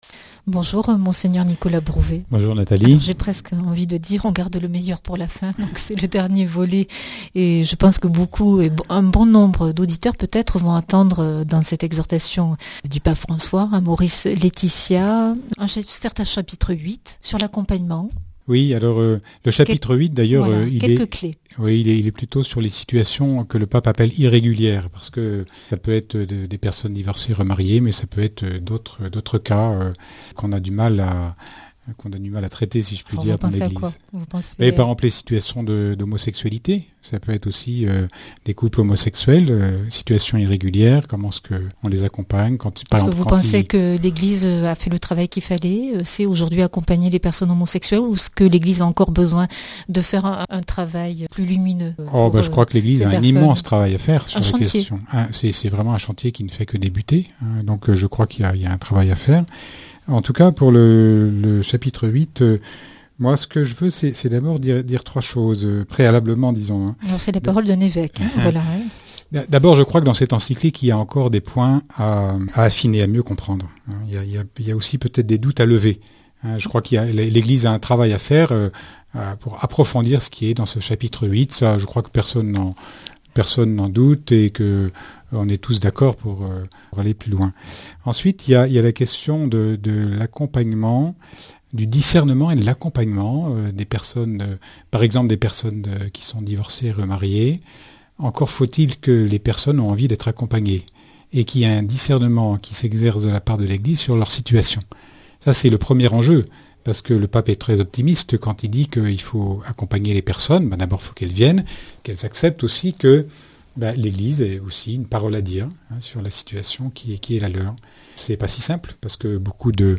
Invité:Mgr Brouwet
Speech